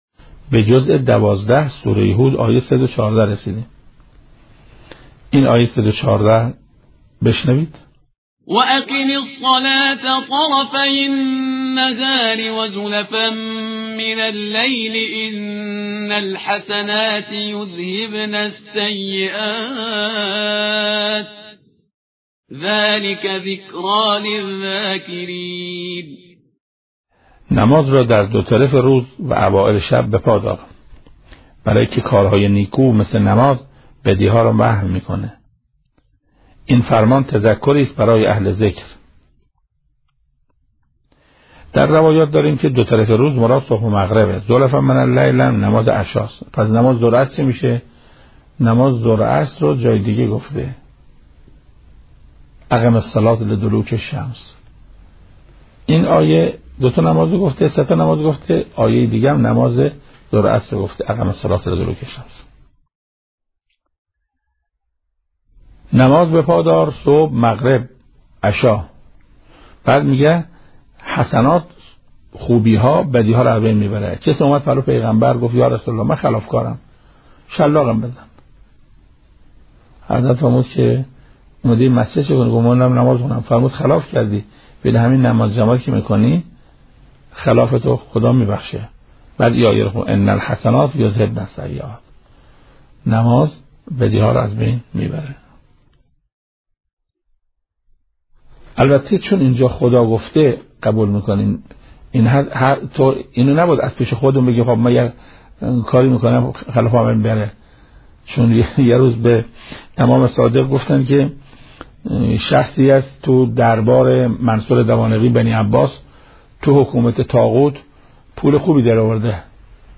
تفسیر آیه ۱۱۴ سوره هود از تفسیر صوتی حجت الاسلام قرائتی
تفسیر